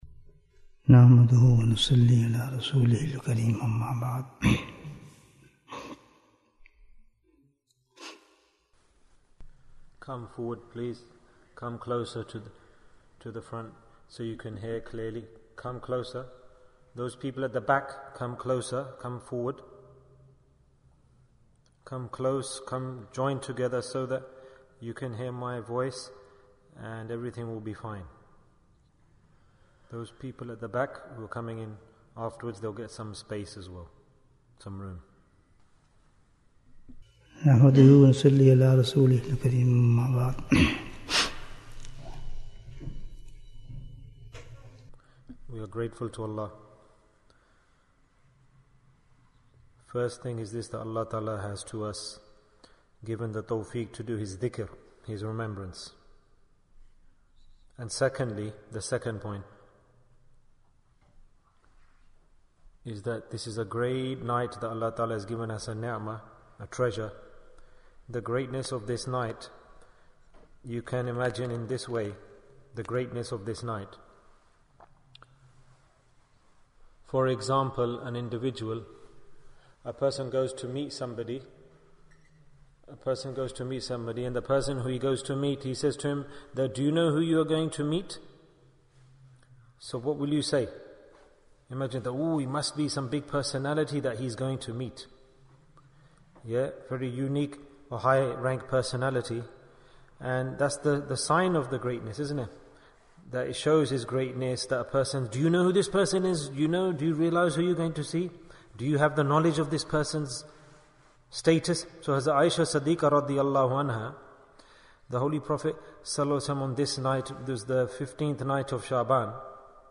Which Ibadah Should be Done on the Night of Baraat? Bayan, 46 minutes6th March, 2023